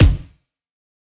taiko-normal-hitnormal.ogg